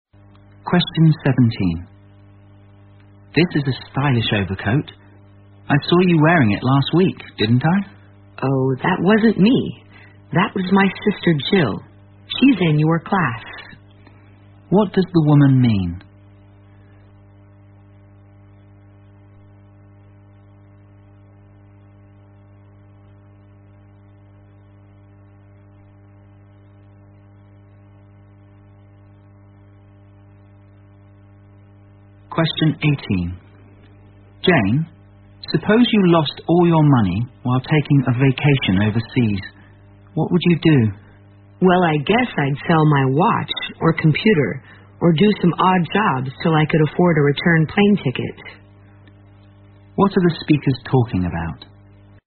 在线英语听力室027的听力文件下载,英语四级听力-短对话-在线英语听力室